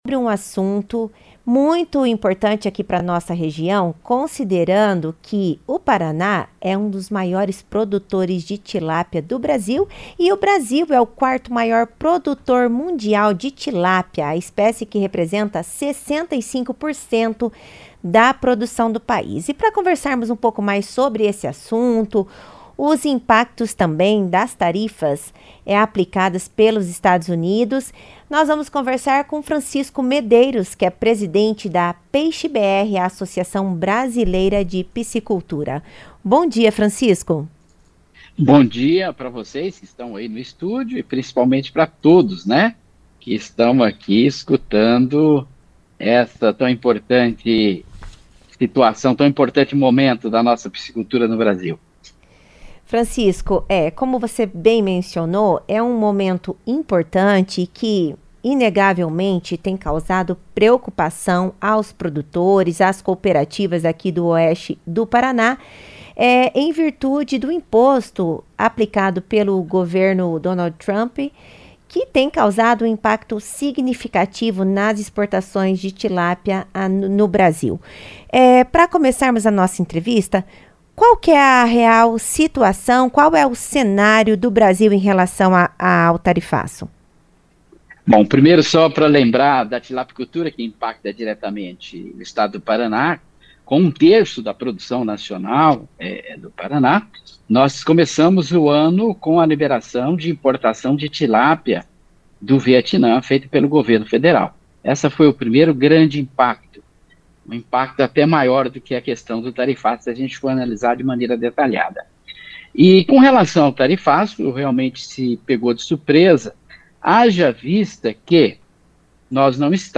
Em entrevista à CBN